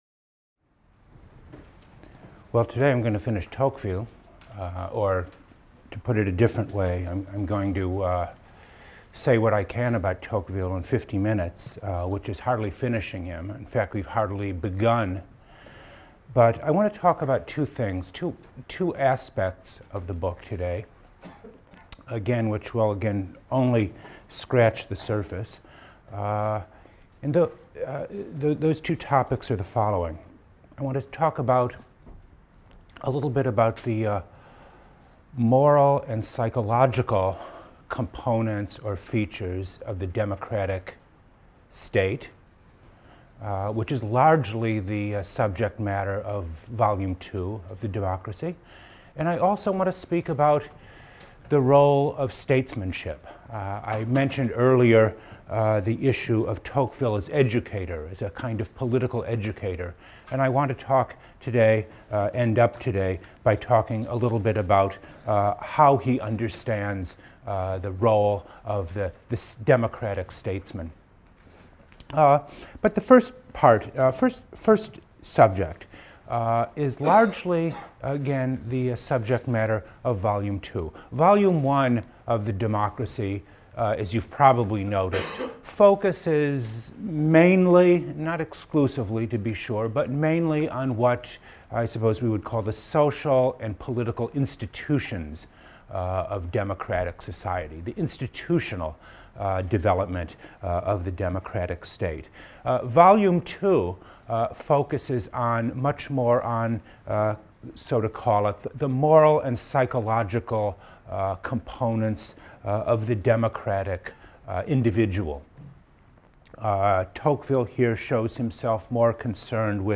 PLSC 114 - Lecture 23 - Democratic Statecraft: Tocqueville, Democracy in America | Open Yale Courses